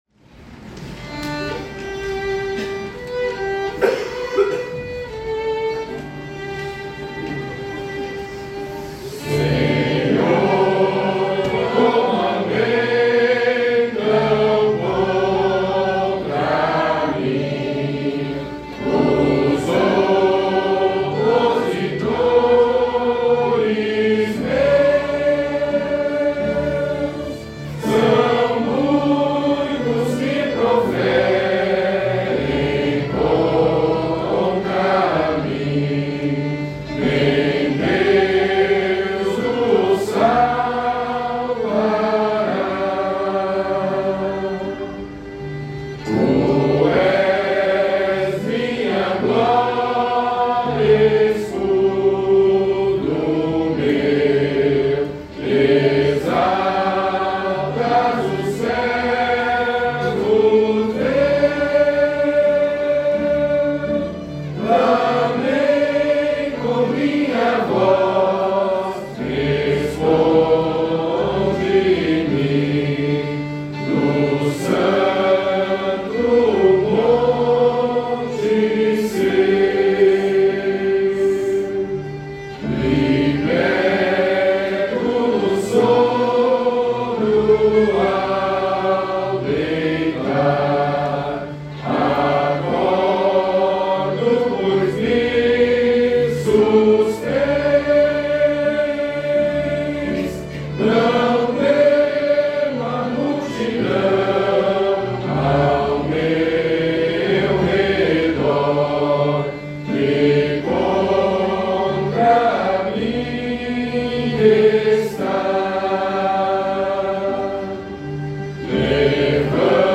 Métrica: 9 7. 10 6
salmo_3B_cantado.mp3